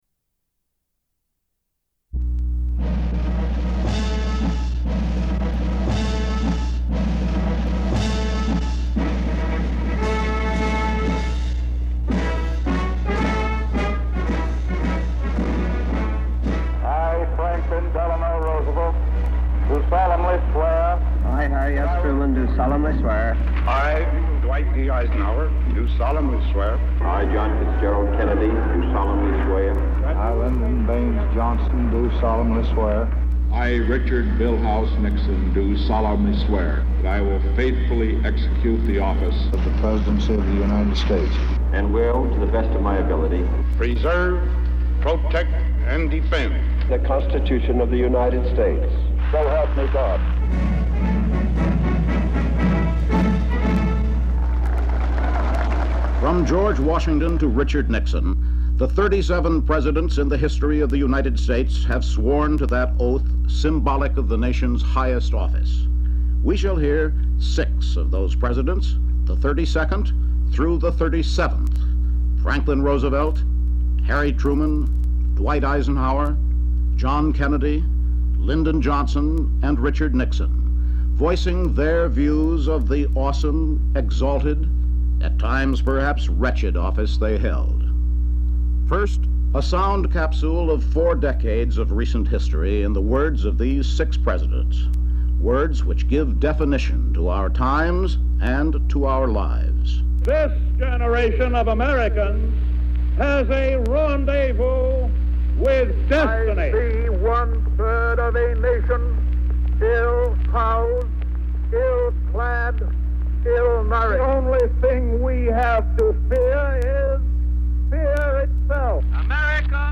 Insights on the office from the presidents themselves. With the voices of former U.S. President's Franklin D. Roosevelt, Harry S. Truman, John F. Kennedy, Dwight D. Eisenhower, Lyndon B. Johnson and current U.S. President Richard Nixon. Narrated by Charles Collingwood.